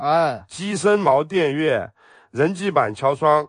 Dai vita ai versi antichi con una voce AI profonda e risonante progettata per voiceover di poesie classiche, recitazioni storiche e narrazione letteraria.
Text-to-Speech
Voce risonante
Recital di poesia
Cadenza naturale e intonazione espressiva adattate ai versi ritmici.